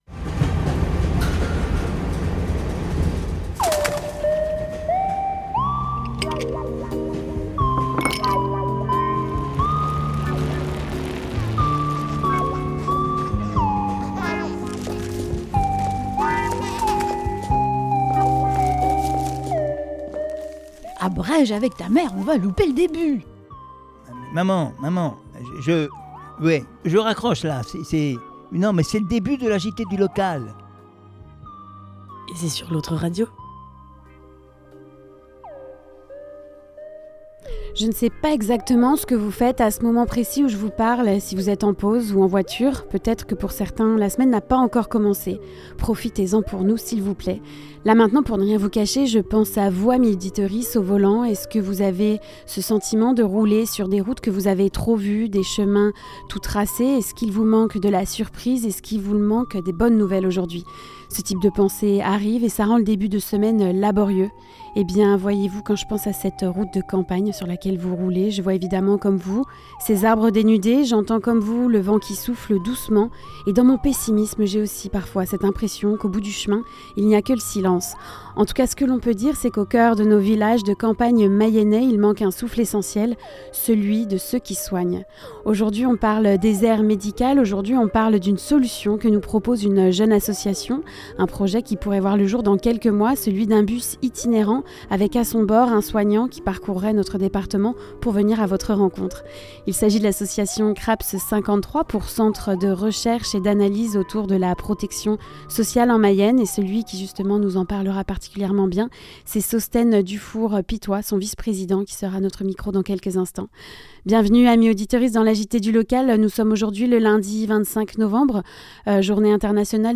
A l'occasion des actions menées autour de la journée internationale de lutte contre les violences faites aux femmes, sur Laval Agglo. 11 témoignages écrits de femmes ont été recueillis par L'Autre Radio et ont ensuite été lu par les membres de l'équipe Sorcières.